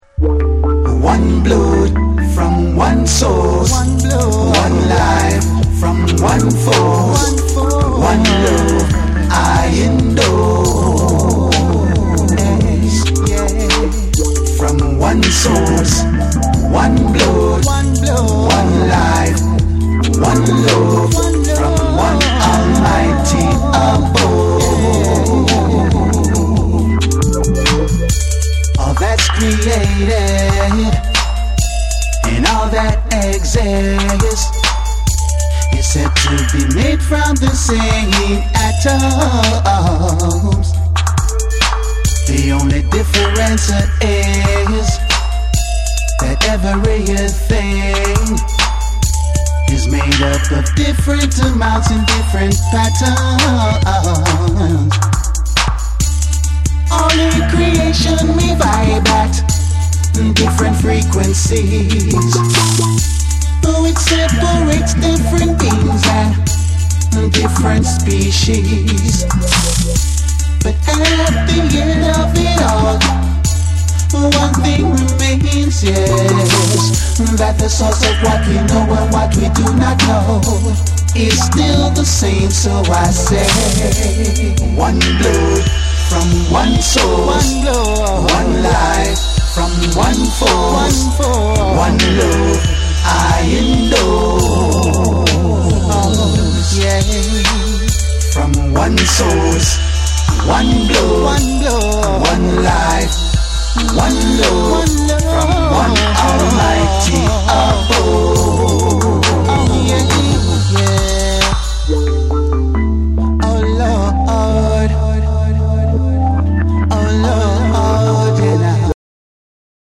BREAKBEATS / DUBSTEP